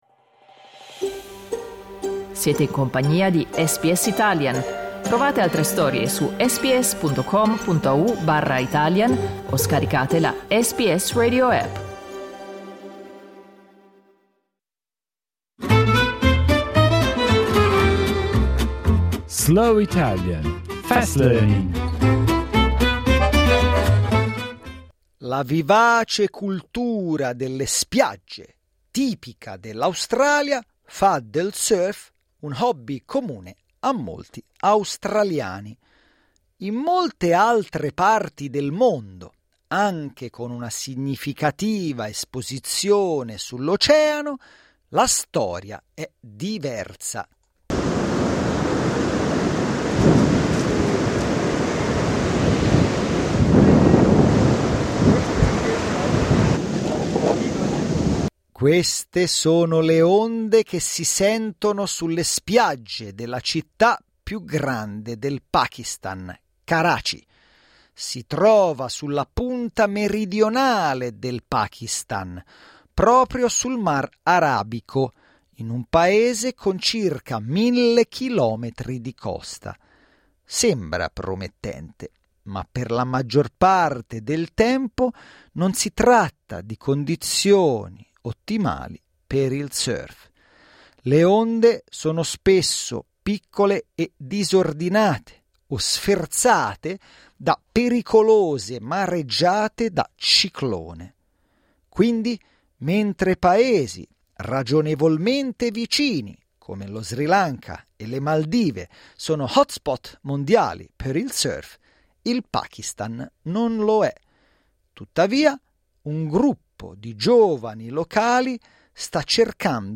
These are the waves heard on the beaches of Pakistan's largest city, Karachi.